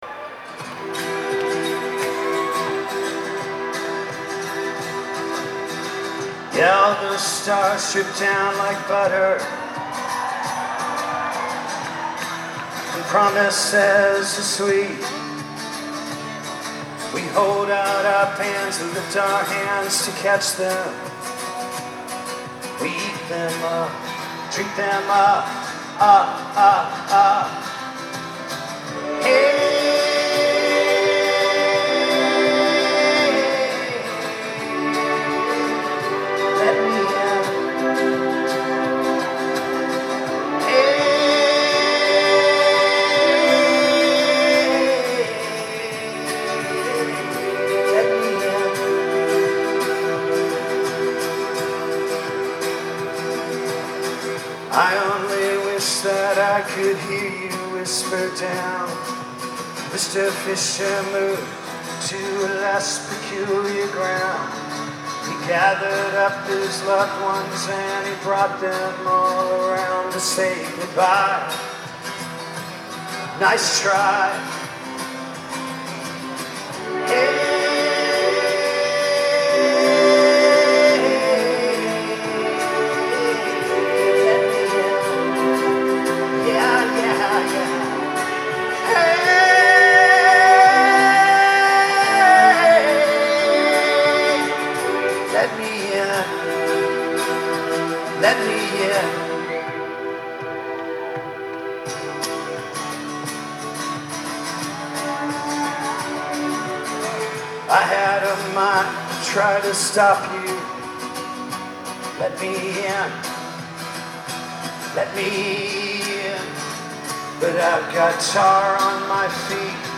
Live at at the Comcast Center